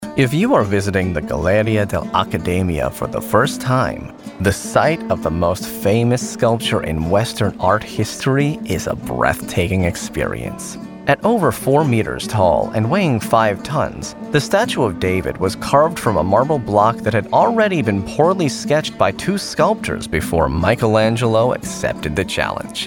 Inglés (Americano)
Natural, Amable, Cálida
Audioguía